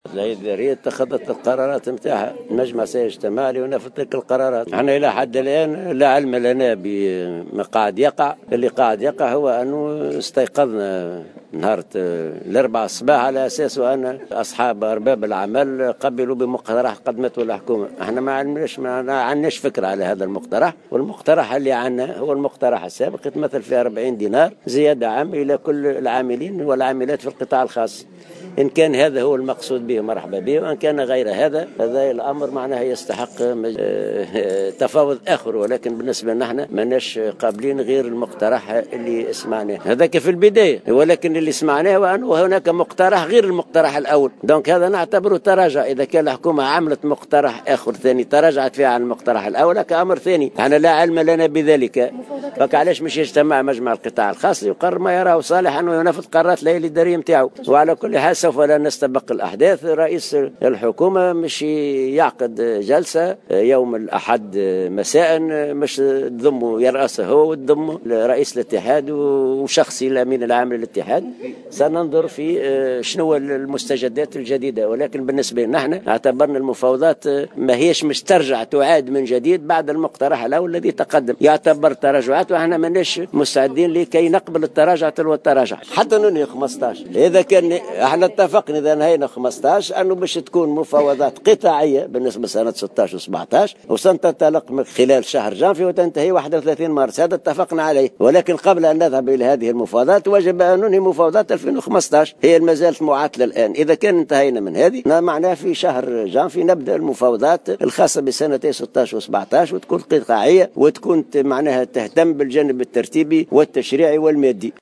Il a précisé, en marge de l’ouverture de la Fédération syndicale des jeunes travailleurs, à Hammamet, que le syndicat ne cèdera pas sur l’accord établi avec le gouvernement et qui stipule une augmentation de 40dt des salaires dans le secteur privé.